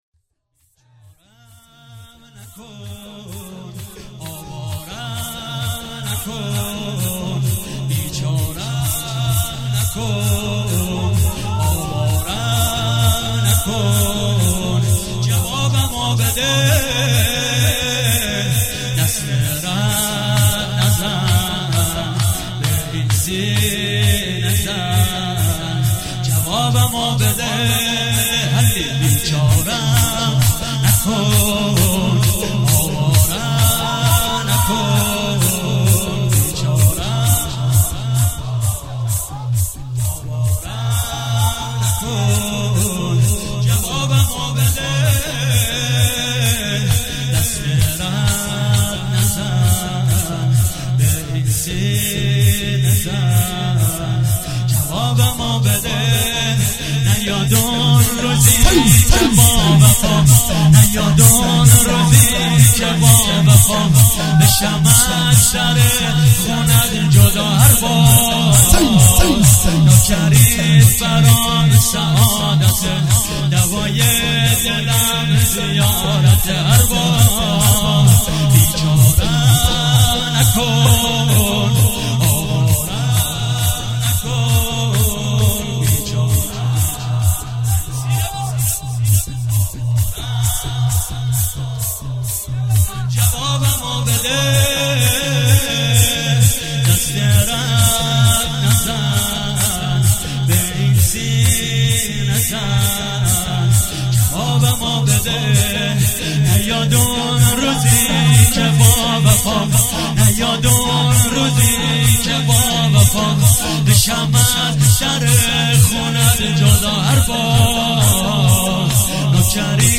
عنوان استقبال از ماه مبارک رمضان ۱۳۹۸
شور